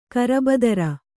♪ karabadara